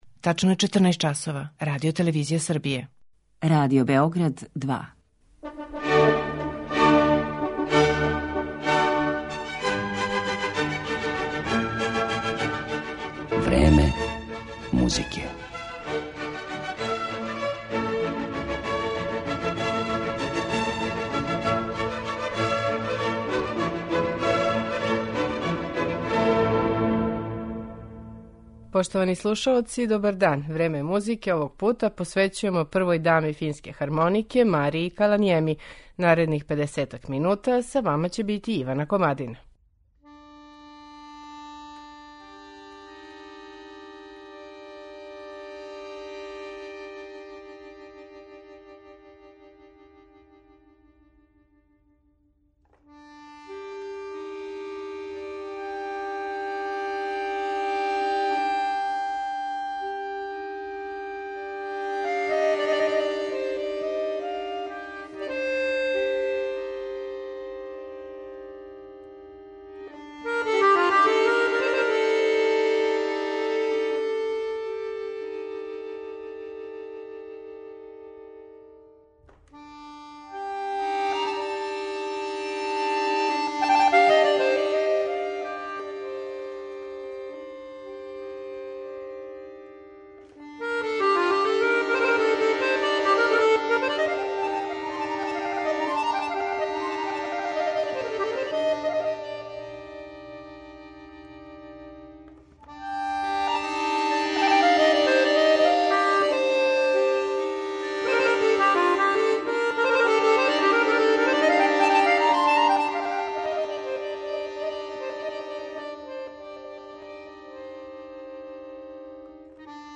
Oсобени стил Марије Каланиеми, прве даме финске хармонике, збуњује музичке критичаре: у фолклором инспирисане мелодије увела је много елемената попа, џеза и уметничке музике и створила нешто што је веома тешко категоризовати, а што се обично квалификује као нова музичка традиција, или, мање срећно, макар са нашим кругом асоцијација, као новокомпонована народна музика.